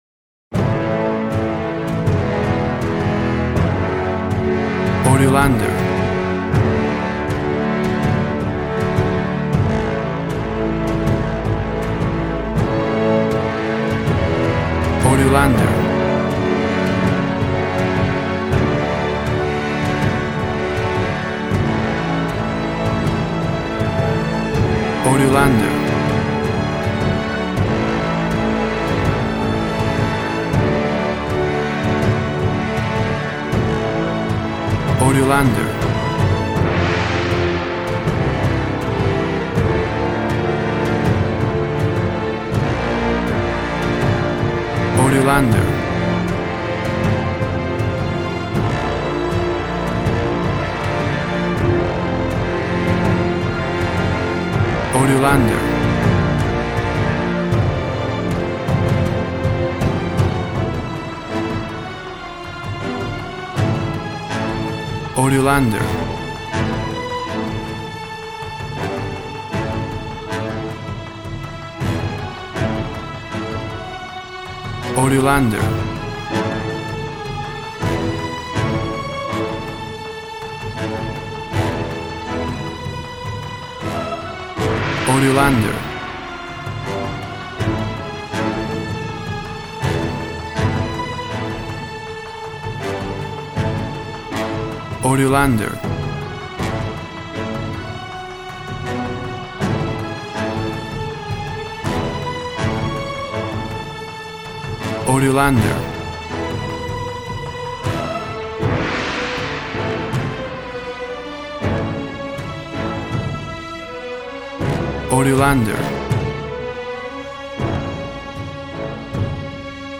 Action and Fantasy music for an epic dramatic world!
Tempo (BPM) 80